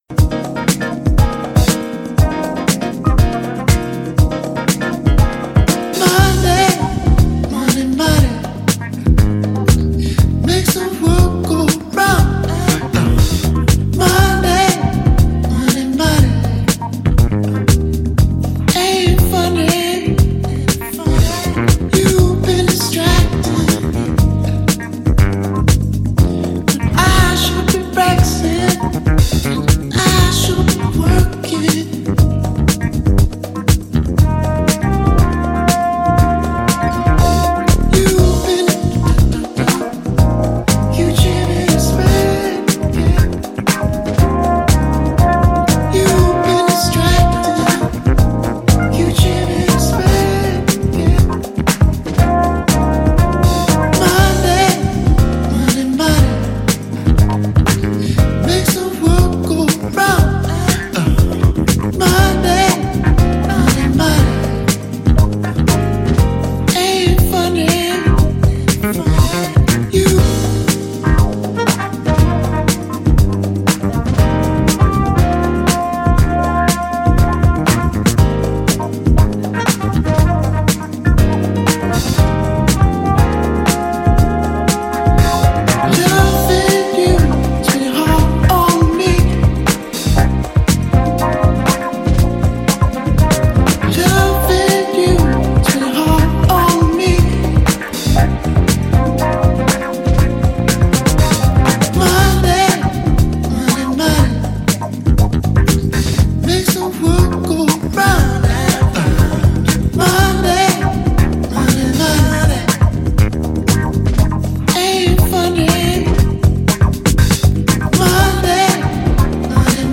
Styl: Disco, House, Breaks/Breakbeat